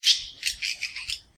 sounds_bat_03.ogg